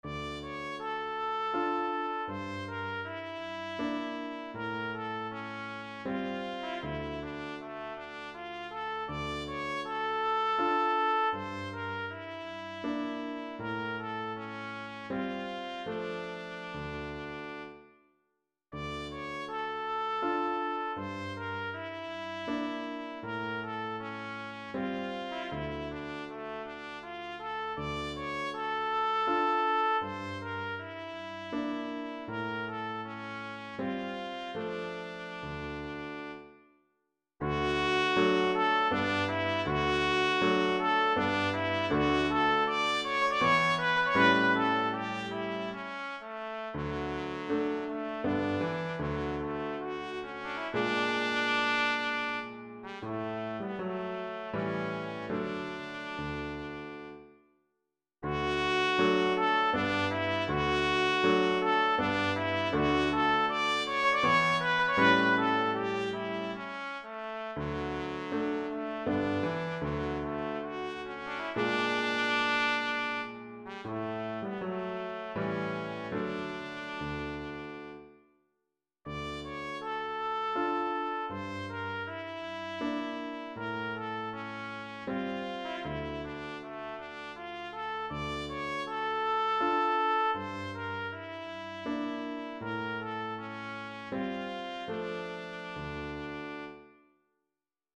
Voicing: Bb Trumpet and Piano